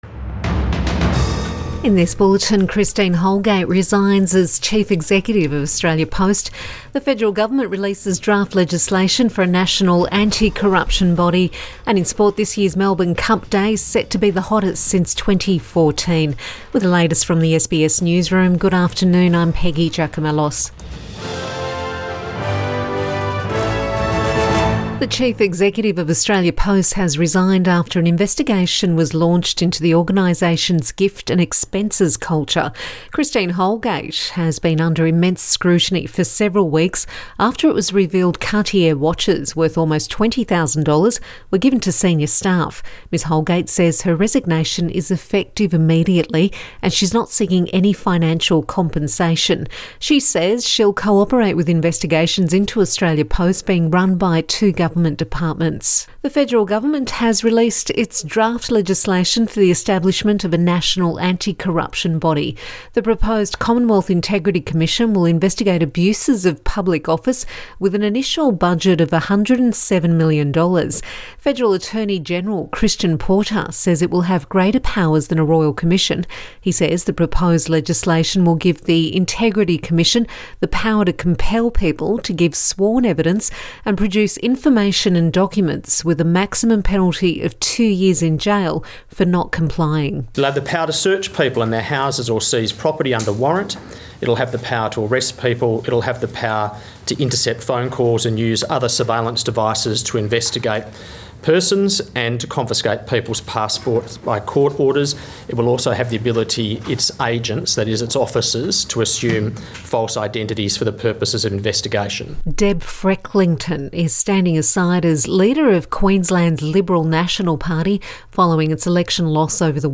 PM bulletin 2 November 2020